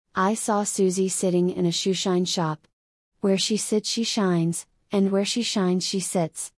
This tongue twister is all about the s and sh sounds, so you can get them just right.